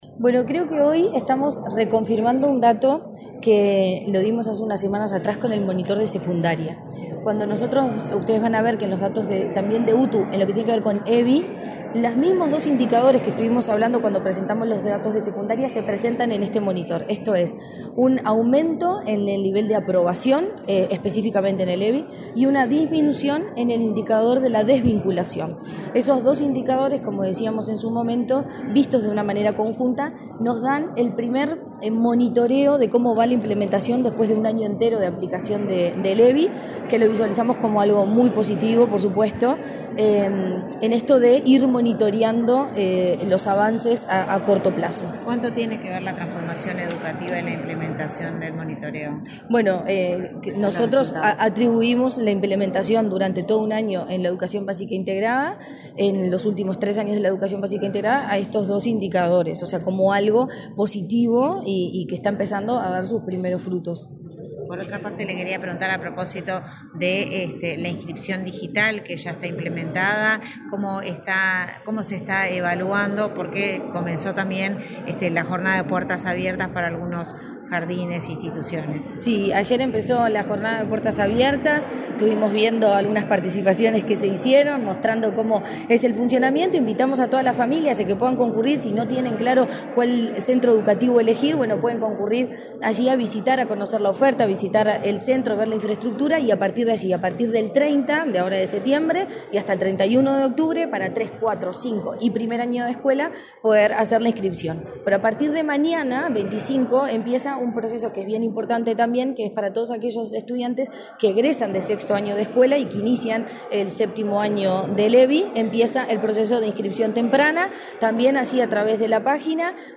Declaraciones de la presidenta de la ANEP, Virginia Cáceres
Declaraciones de la presidenta de la ANEP, Virginia Cáceres 24/09/2024 Compartir Facebook X Copiar enlace WhatsApp LinkedIn La presidenta de la Administración Nacional de Educación Pública (ANEP), Virginia Cáceres, dialogó con la prensa, antes de participar en la presentación del Monitor Educativo de Enseñanza Media Técnico Profesional 2023-2024.